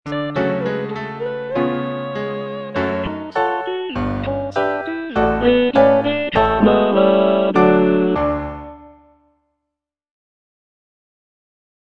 (bass II) (Emphasised voice and other voices) Ads stop